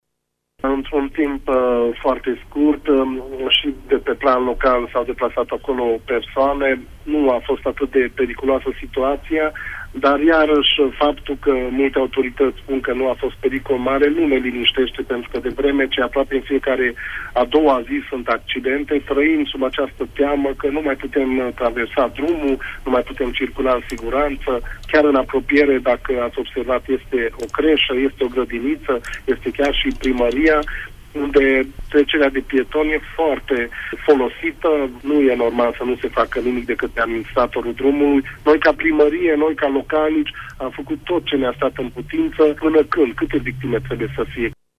Accidentul s-a produs în zona Primăriei Sângiorgiu de Mureş, iar edilul comunei, Sofolvi Szabolcs a declarat pentru RTM că, deși situația a fost remediată urgent, oamenii trăiesc cu frică din cauza accidentelor frecvente din zonă: